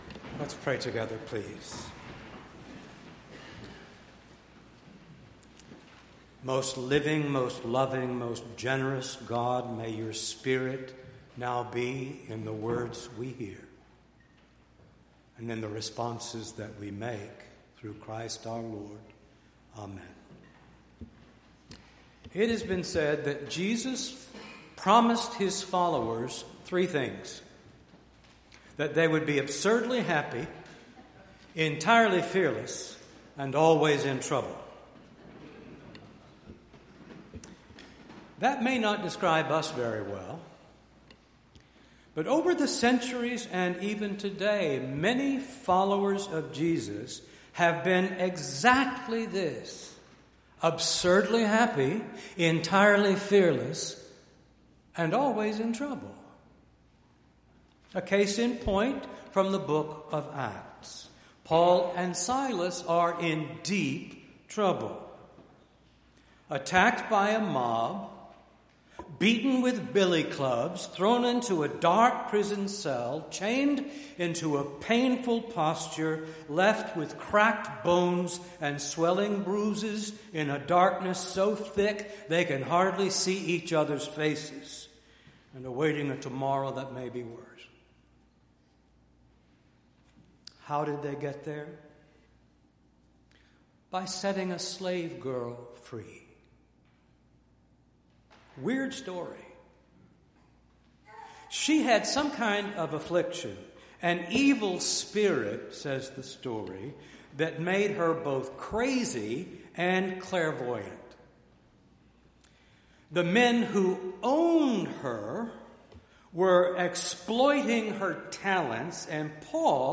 6-2-19-sermon.mp3